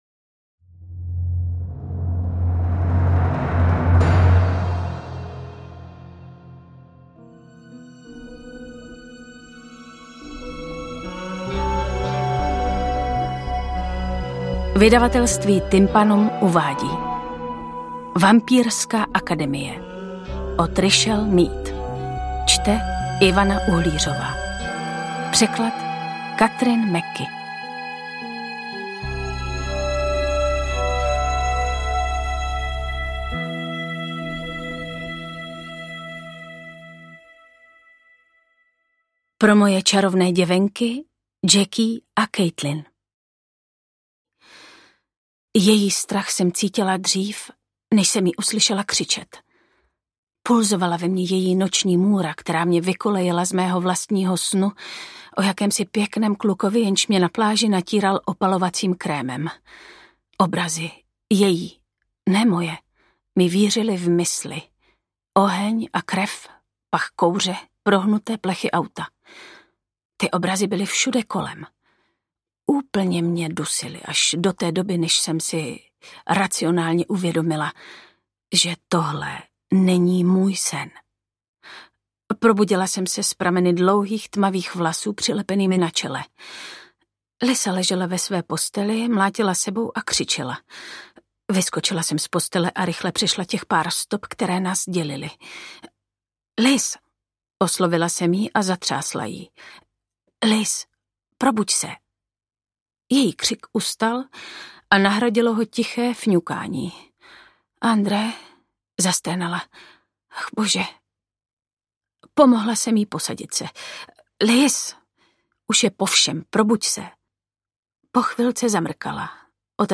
Interpret:  Ivana Uhlířová
AudioKniha ke stažení, 28 x mp3, délka 11 hod. 45 min., velikost 650,4 MB, česky